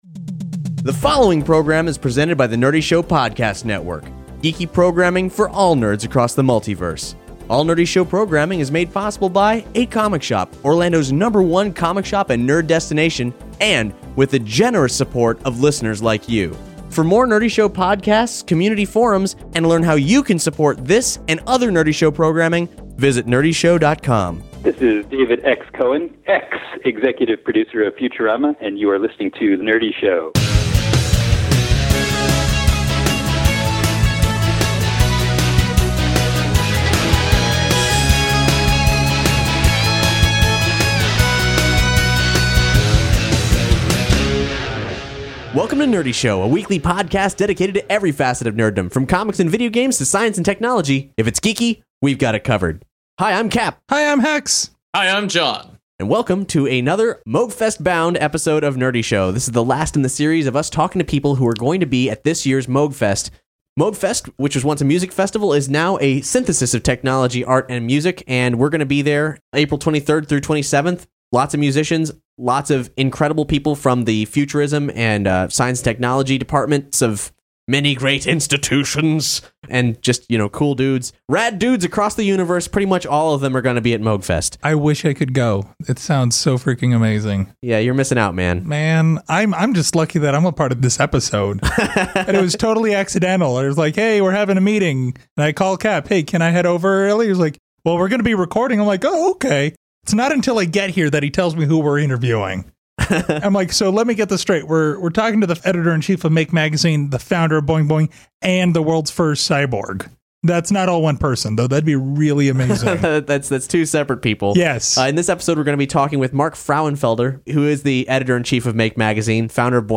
Our interview series leading up to Moogfest concludes!